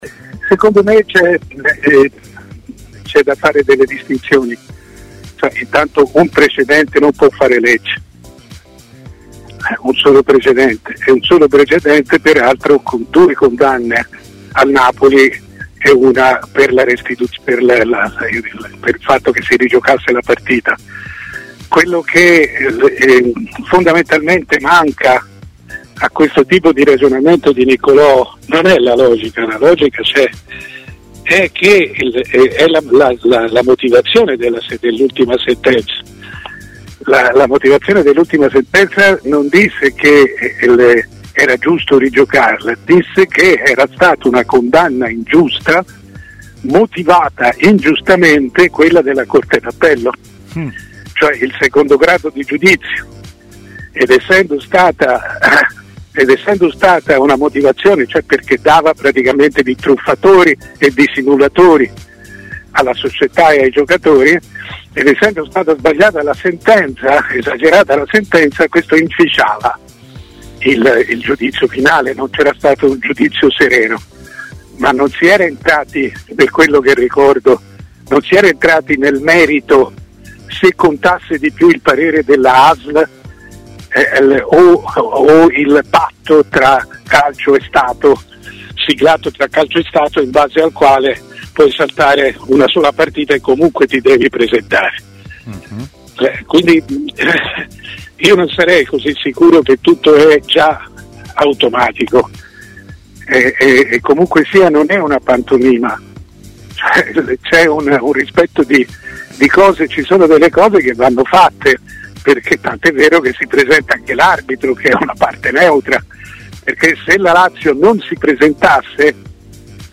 Fonte: TMW Radio